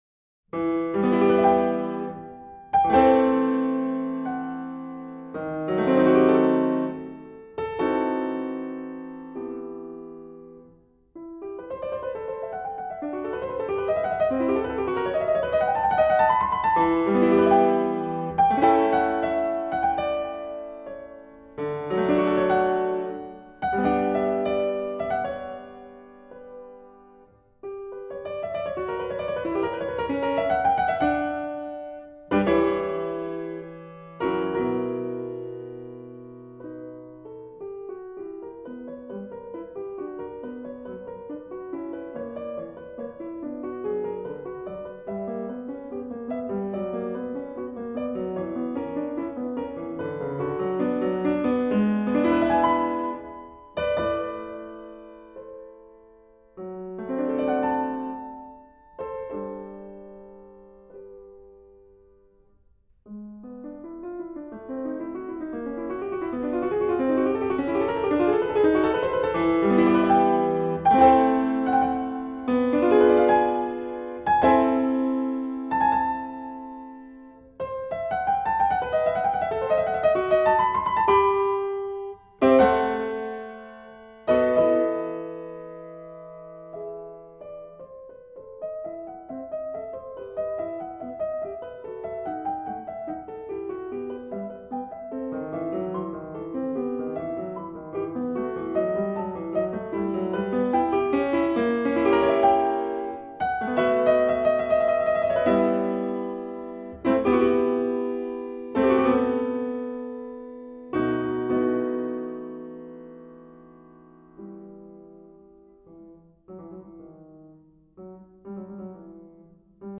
e-moll